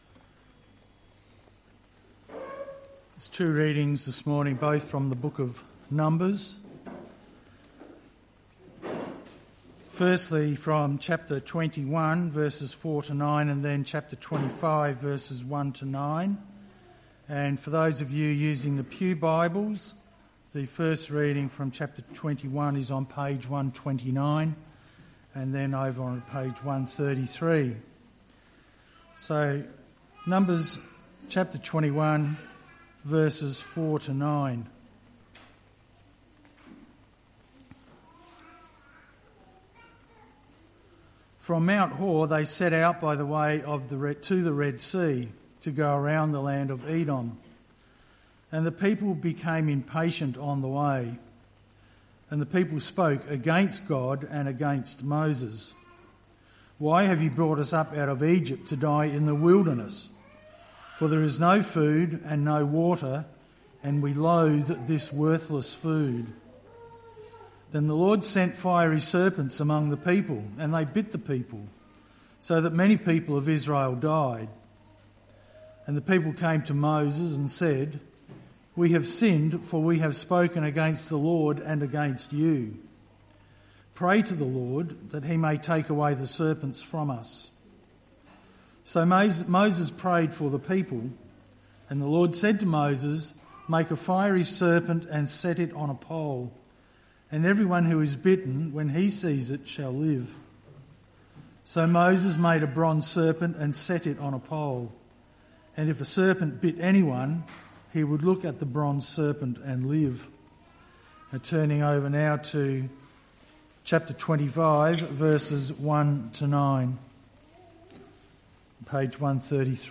Mar 30, 2025 We need to know the wrath of God for the gospel of God to make sense MP3 SUBSCRIBE on iTunes(Podcast) Notes 30th of March 2025 - Morning Service Numbers 21:4–9 Numbers 25:1–9 Romans 1:8-2:5